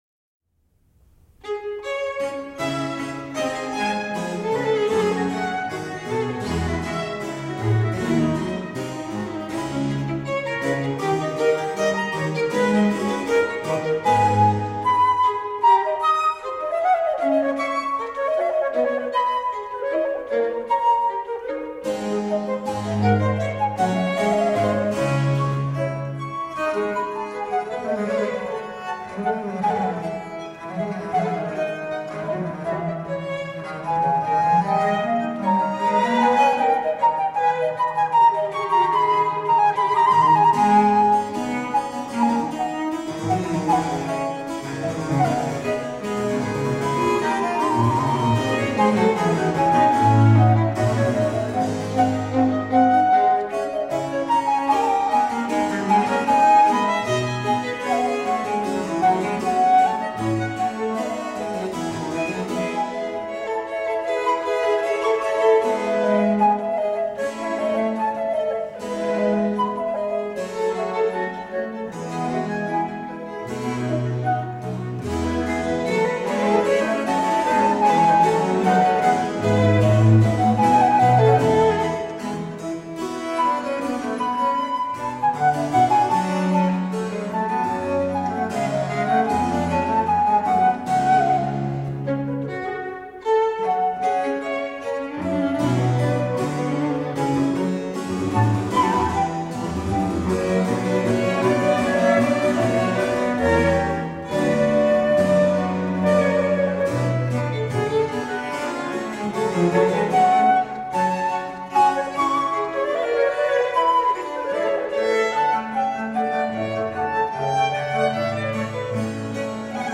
Rare and extraordinary music of the baroque.
lightly elegant dance music
violinist
flutist
harpsichord
violoncello) whose supple tones compliment the overall mood.